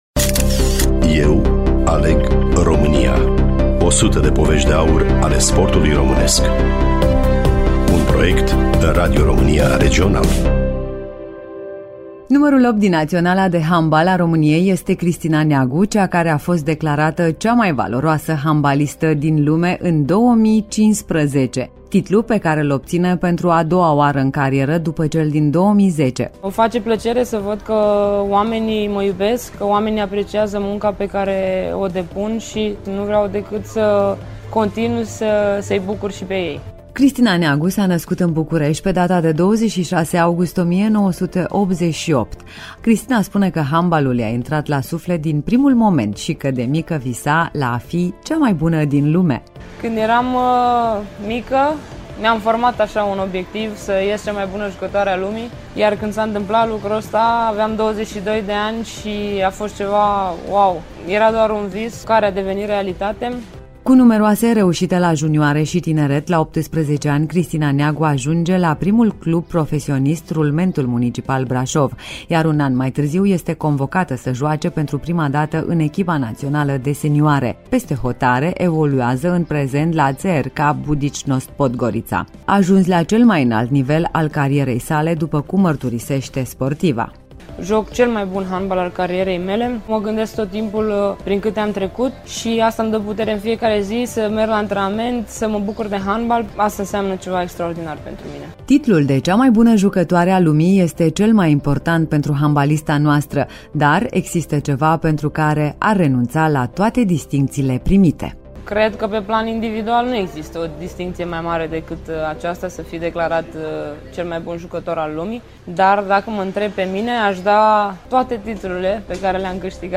Studioul Radio România Reşiţa
Interviul a fost realizat de Federația Română de Handbal